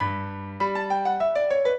piano
minuet12-12.wav